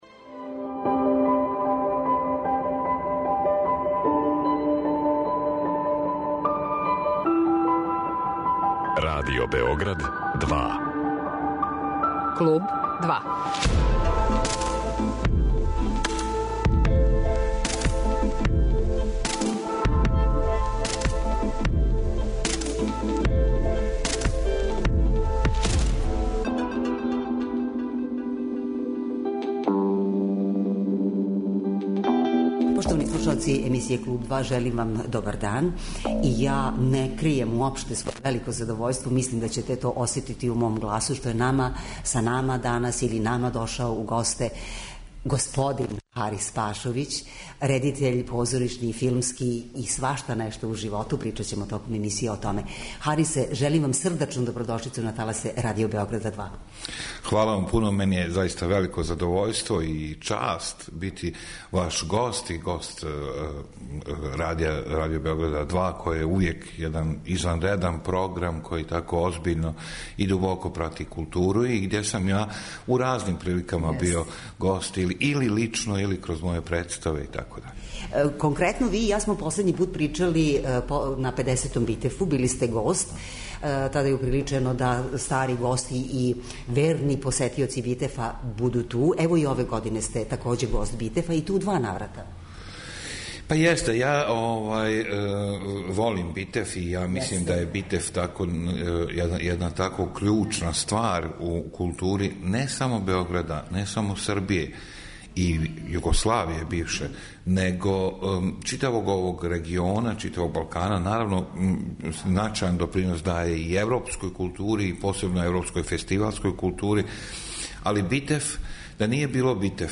Гост емисије Клуб 2 је Харис Пашовић, позоришни редитељ из Сарајева, гост Битефа и причамо о његовом раду.